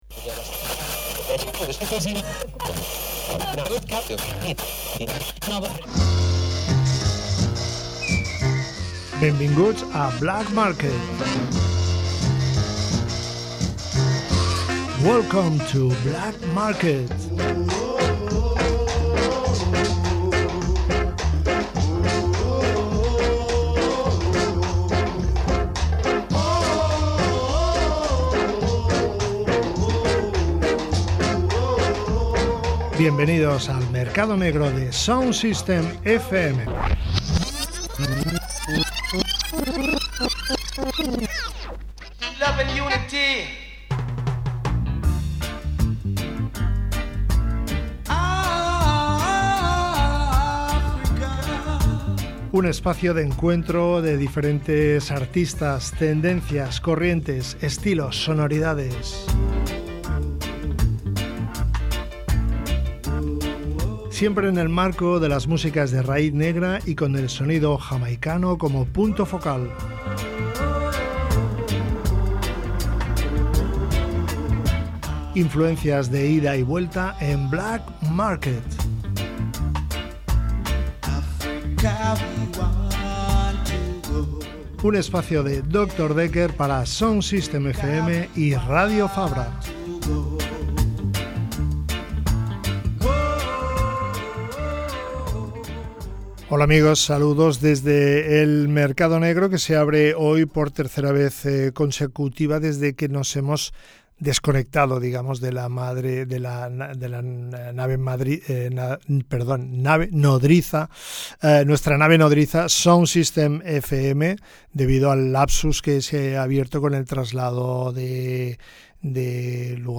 Acompañanos en un viaje musical por Panama, Puerto Rico y la costa caribeña centroamericana, para descubrir las raices del reggaeton y el dancehall en español.